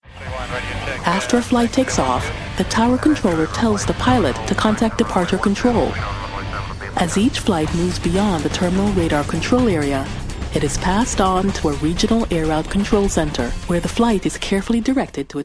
A vibrant, classy, intelligent North American voice talent, specializing in commercial, documentary and corporate narration.
My dual nationality background and international experience brings you a voice with standard American diction that is familiar and comfortable for your international audience.
Sprechprobe: Industrie (Muttersprache):